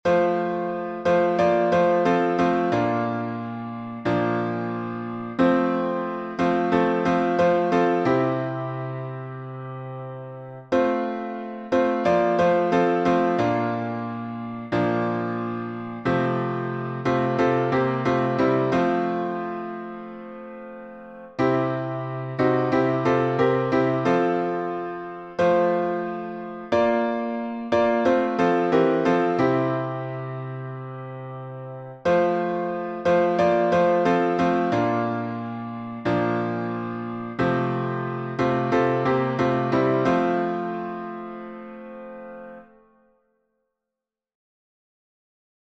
What a Friend We Have in Jesus — alternate harmonies.